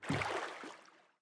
Minecraft Version Minecraft Version latest Latest Release | Latest Snapshot latest / assets / minecraft / sounds / entity / boat / paddle_water5.ogg Compare With Compare With Latest Release | Latest Snapshot
paddle_water5.ogg